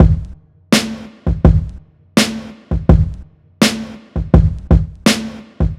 Navy Drum Loop.wav